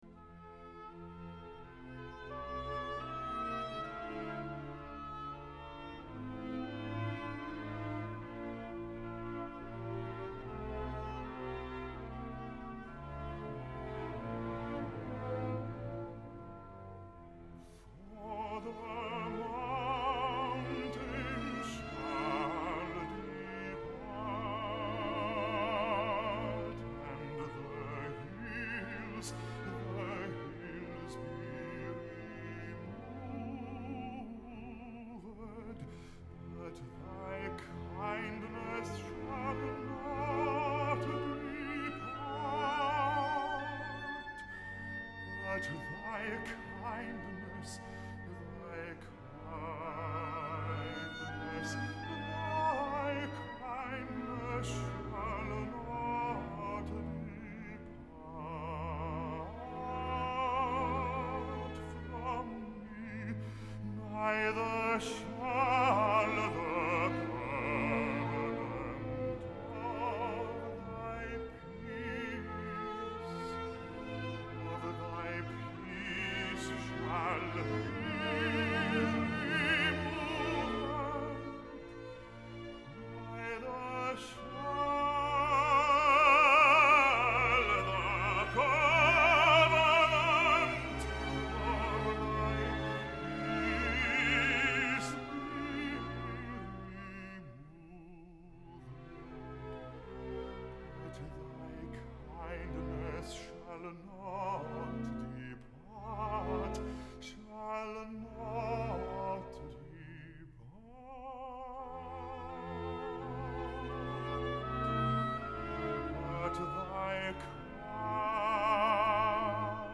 오라토리오 <엘리야> Elijah   Op.70
아리오소(엘리야) - 산이 무너진다 해도
elijah_39_arioso.mp3